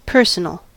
personal: Wikimedia Commons US English Pronunciations
En-us-personal.WAV